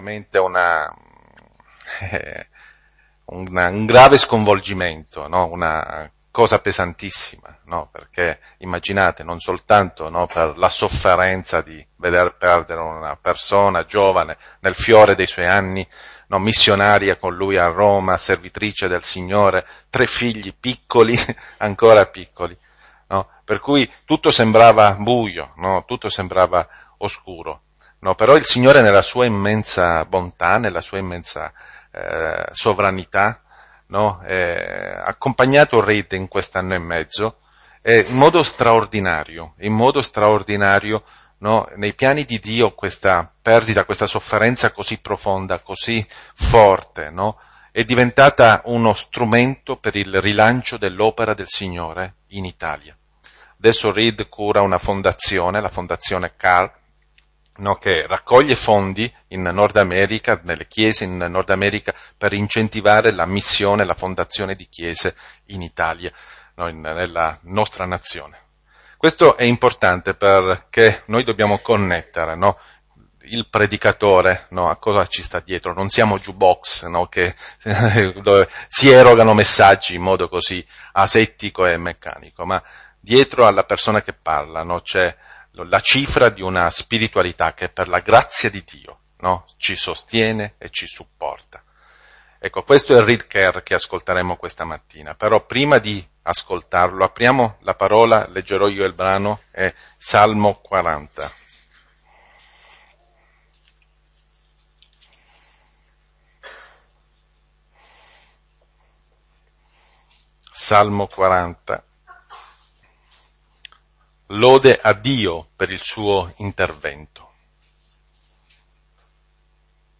Predicazioni